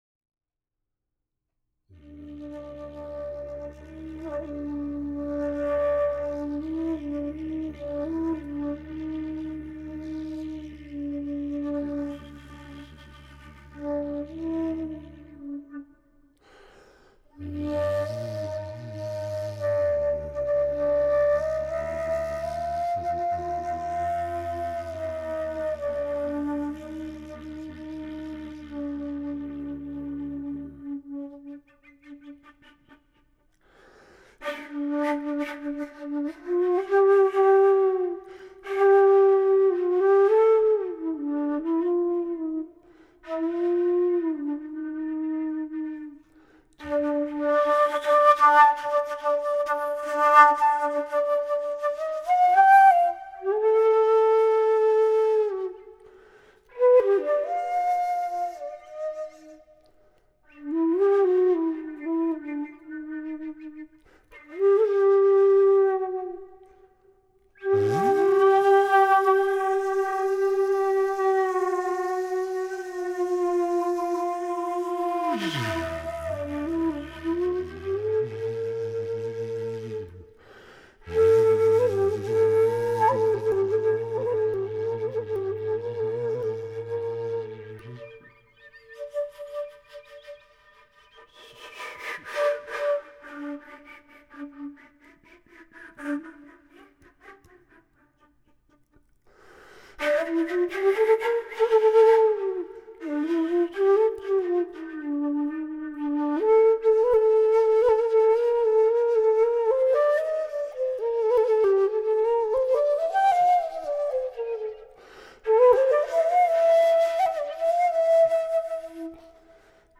Bamboo flute